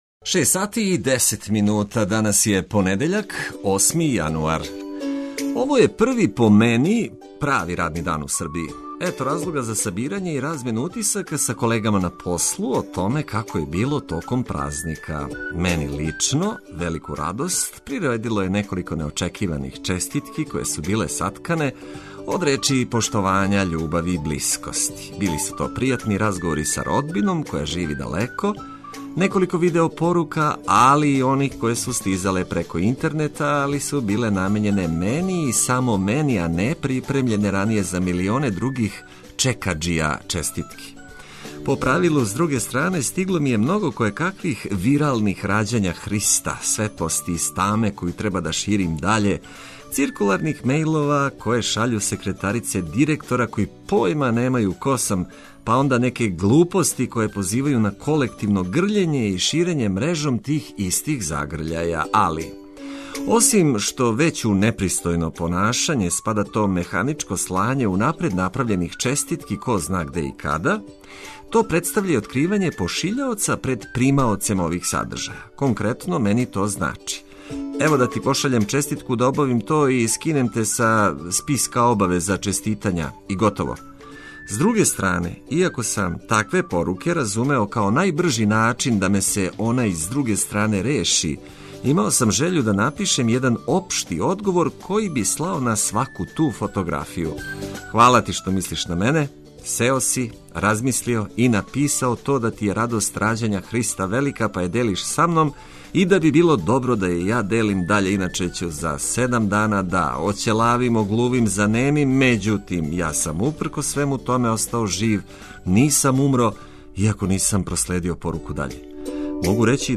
Наша препорука за ово топло зимско јутро је да се разбудите у нашем друштву добро расположени уз ведрину музику и корисне инфромације за почетак првог правог радног дана у овој години.